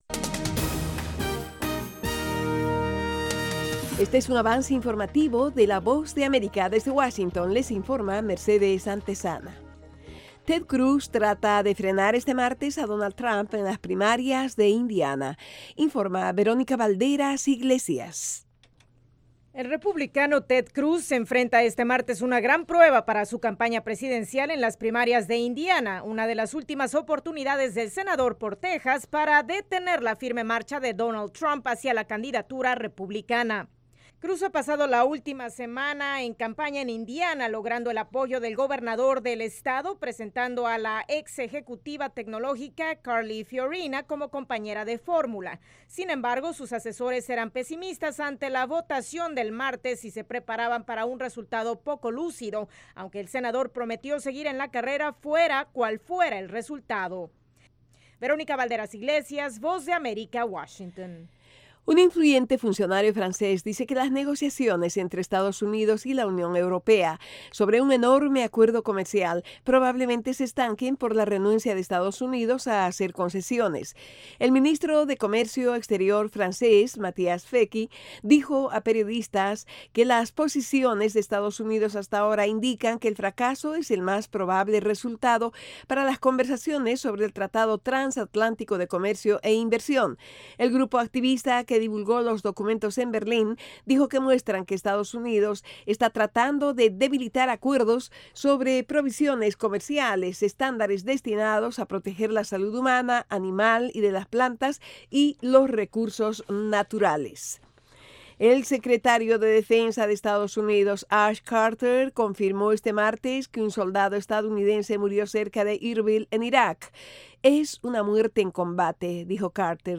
Capsula informativa de 5 minutos con el acontecer noticioso de Estados Unidos y el mundo.